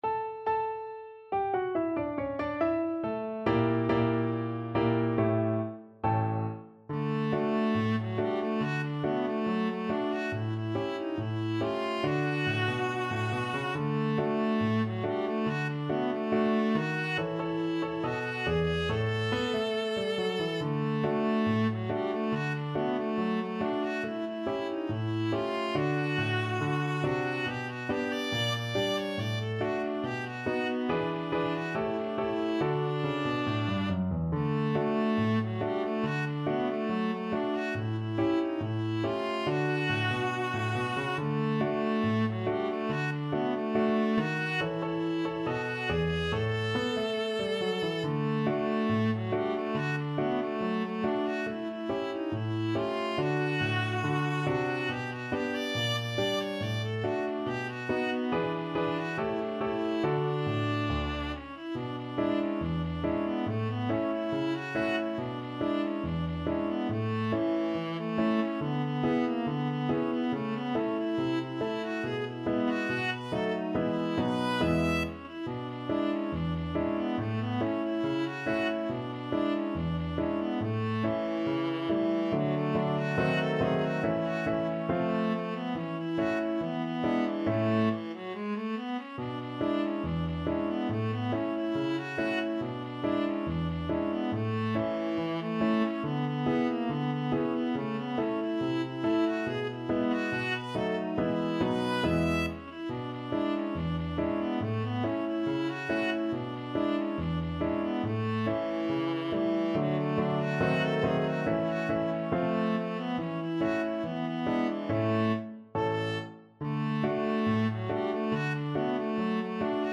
Not fast Not fast. = 70
Jazz (View more Jazz Viola Music)